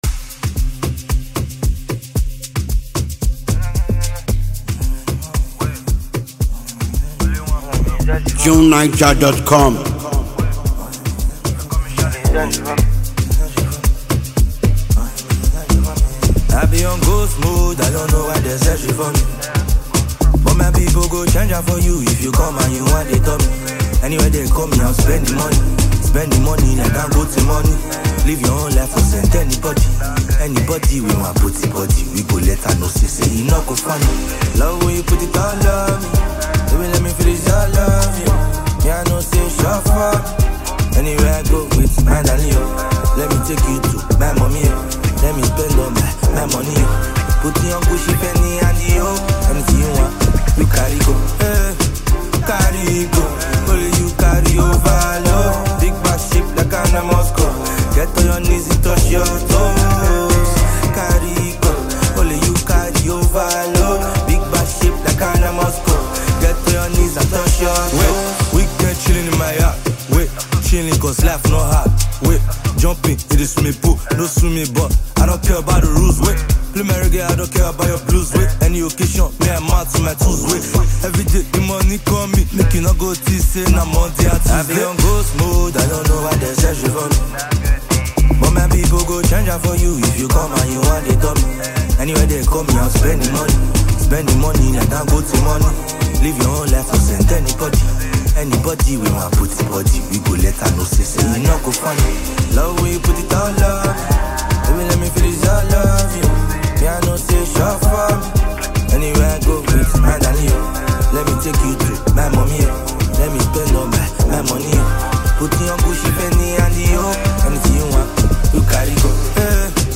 Nigerian musical performer and vocalist
reveals a softer, more sensitive side